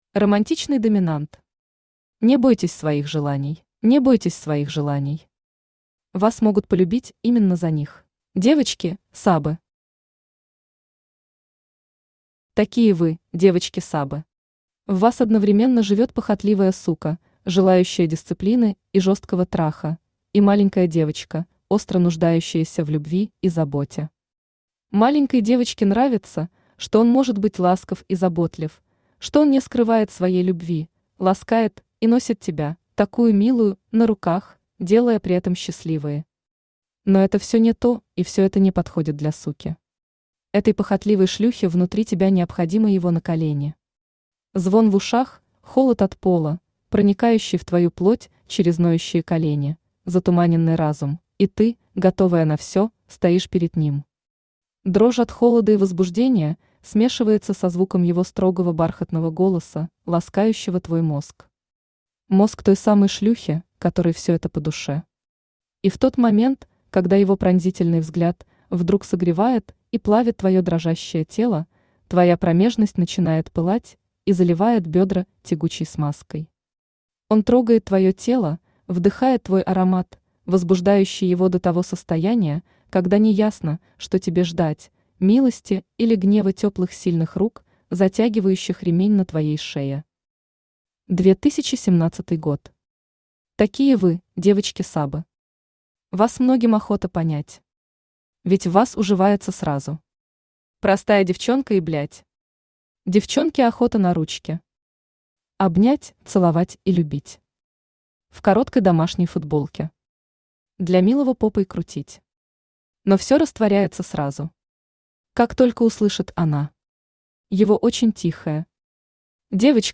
Аудиокнига Не бойтесь своих желаний | Библиотека аудиокниг
Aудиокнига Не бойтесь своих желаний Автор Романтичный Доминант Читает аудиокнигу Авточтец ЛитРес.